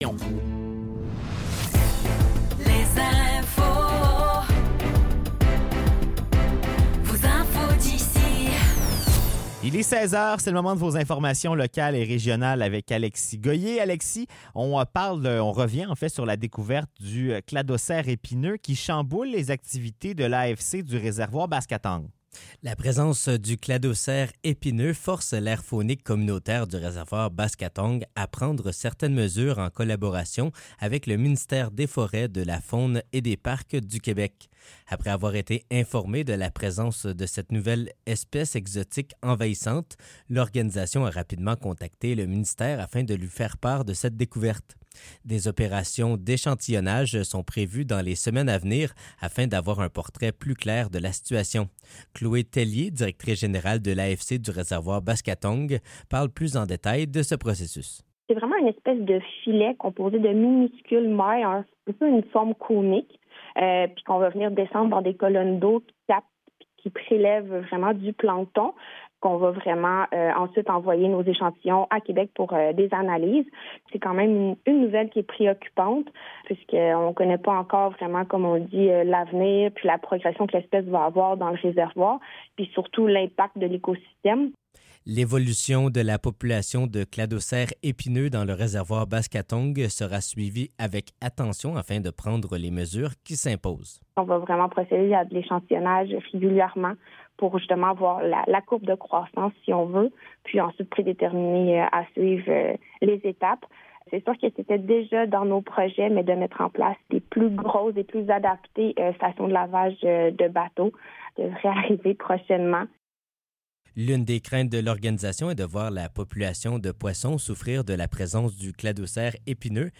Nouvelles locales - 3 octobre 2024 - 16 h